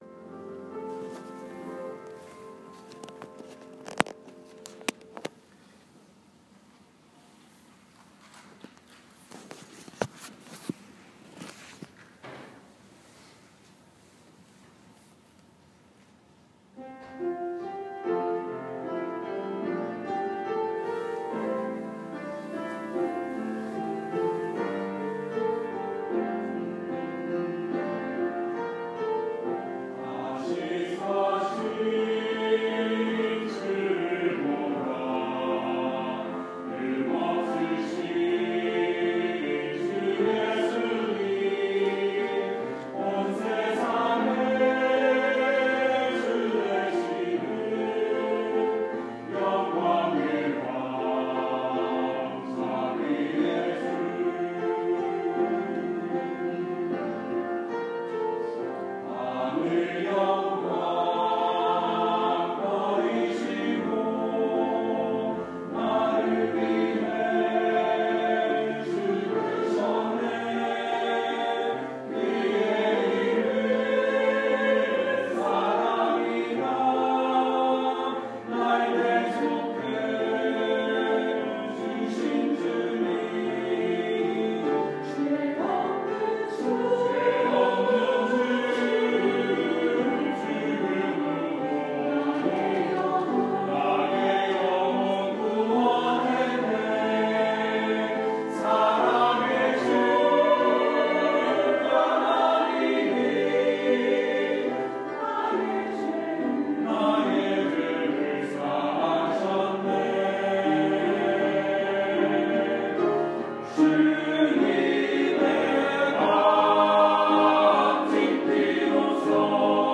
4월 3일 주일 찬양대(다시 사신 주를 보라)